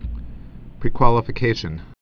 (prēkwŏlə-fĭ-kāshən)